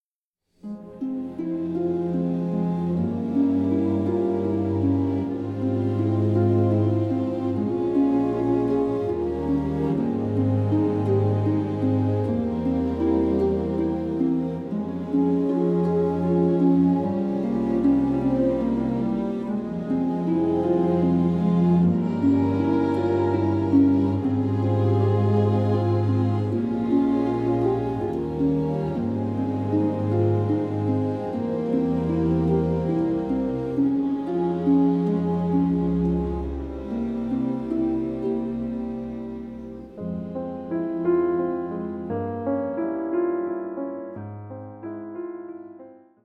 like a romantic and nostalgic musical journey.